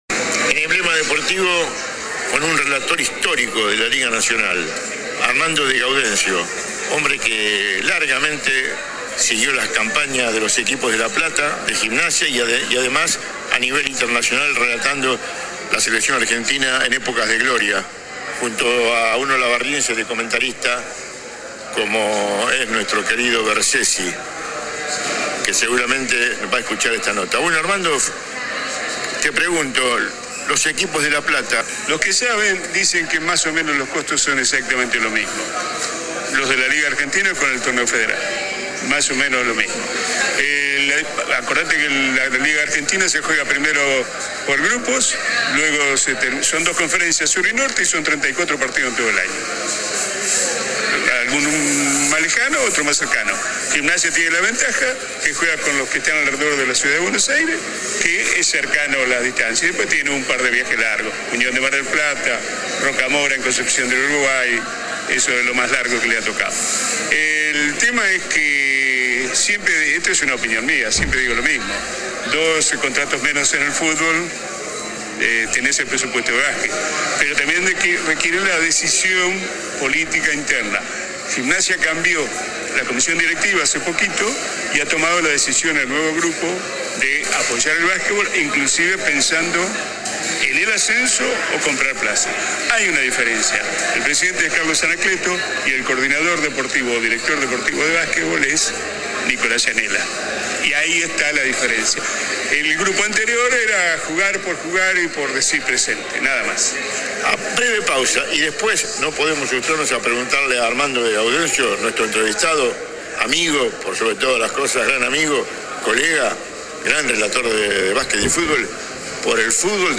AUDIO DE LA ENTREVISTA ( en dos bloques )